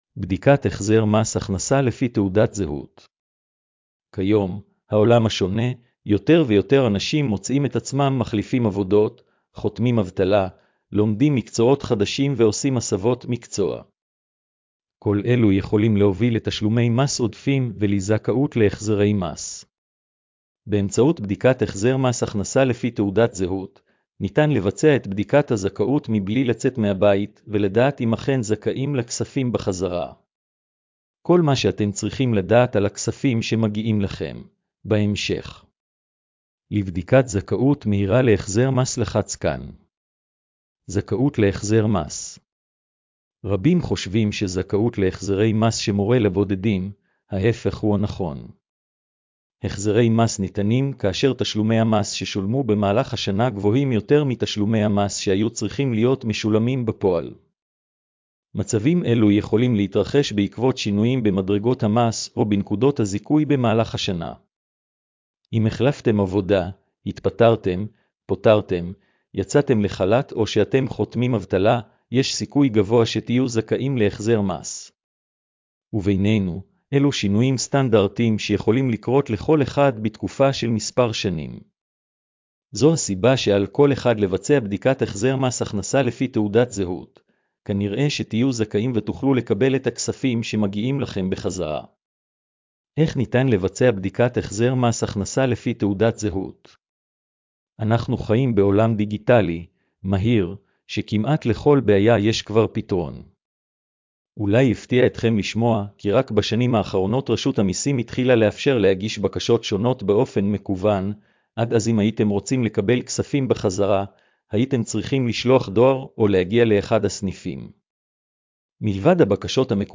הקראת המאמר לבעלי מוגבלויות: